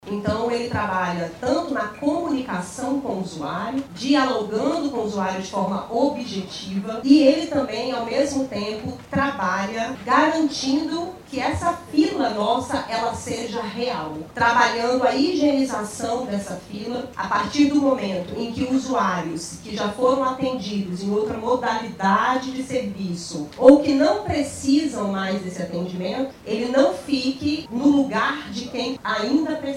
SONORA-2-NAIARA-MAKSUDE.mp3